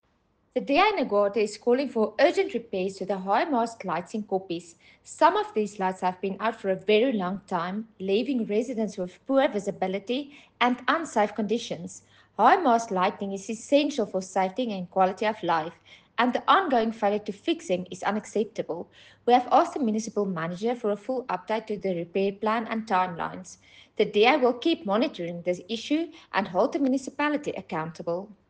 Afrikaans soundbites by Cllr Carina Serfontein and Sesotho soundbite by Cllr Kabelo Moreeng.